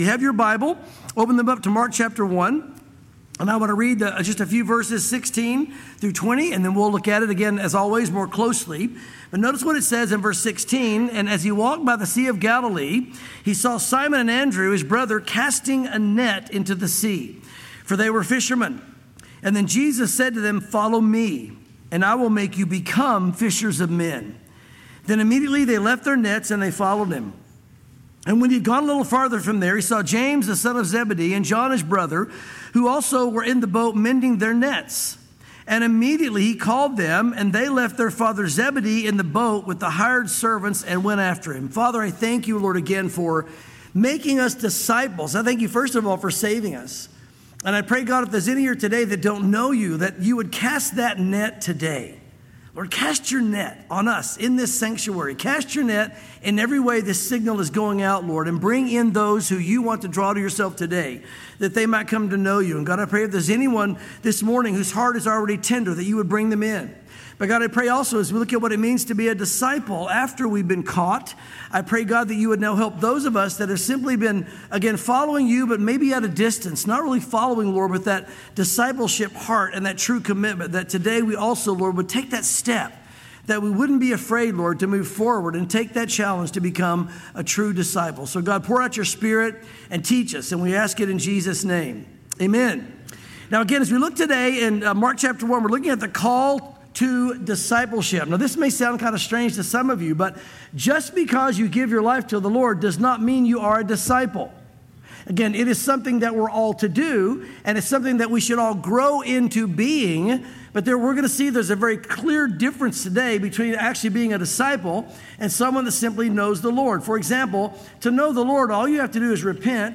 sermons
Calvary Chapel Knoxville